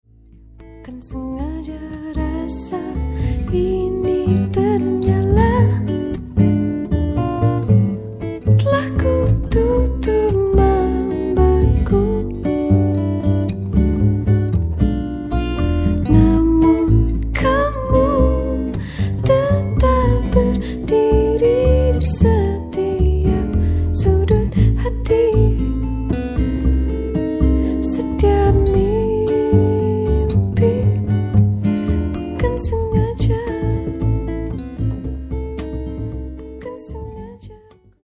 彼女の表情豊かな歌声に癒される